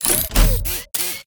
Sfx_tool_hoverpad_build_start_01.ogg